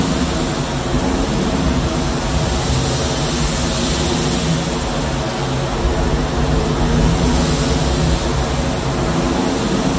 Bruitage – Ouragan – Le Studio JeeeP Prod
Bruitage haute qualité créé au Studio.
Ouragan.mp3